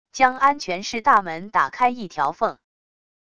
将安全室大门打开一条缝wav音频